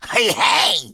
行为语音下载